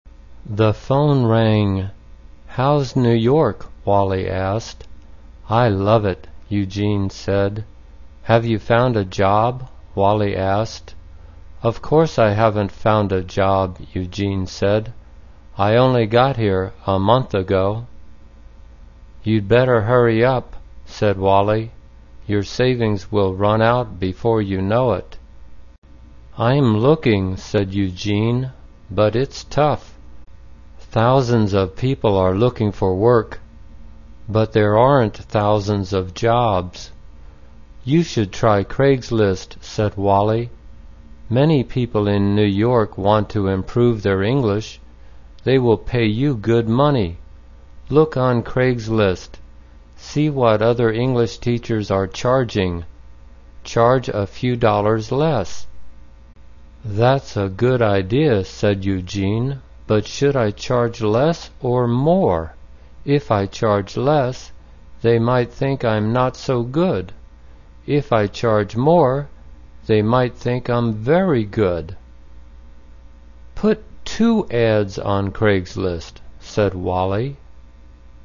简单慢速英语阅读：Put an Ad on Craigslist 听力文件下载—在线英语听力室